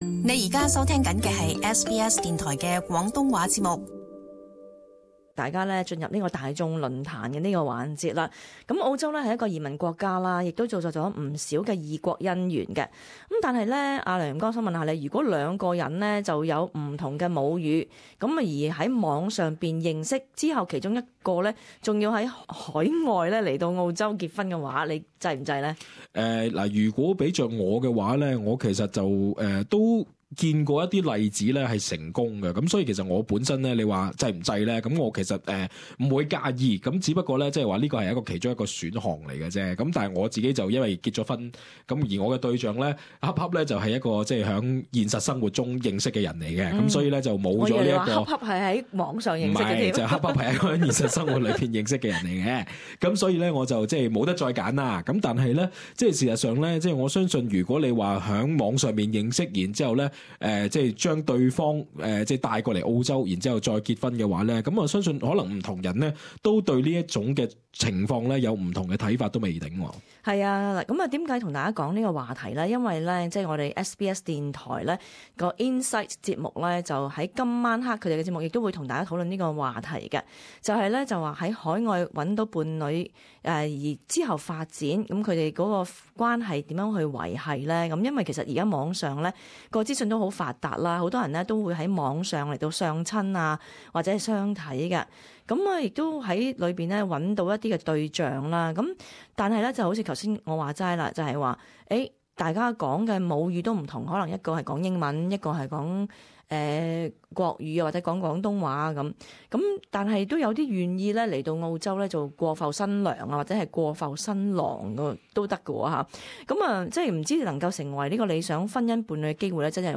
與聽眾一起討論。